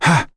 Zafir-Vox-Sur2.wav